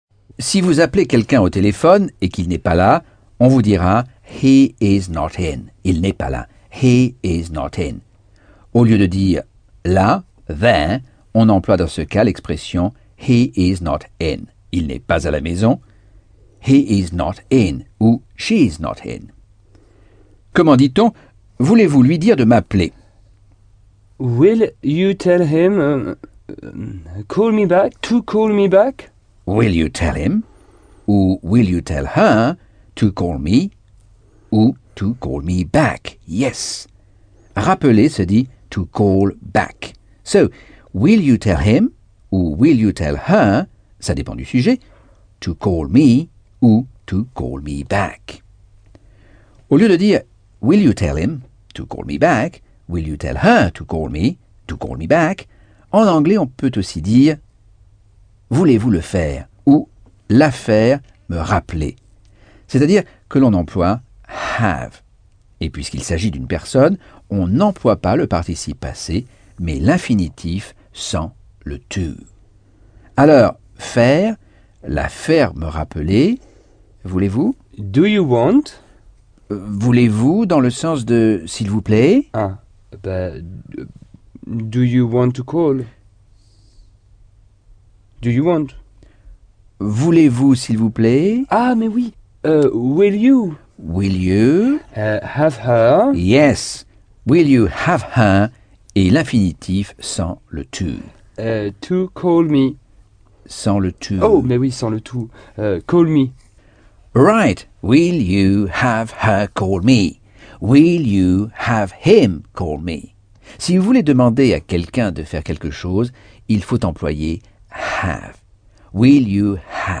Leçon 6 - Cours audio Anglais par Michel Thomas - Chapitre 10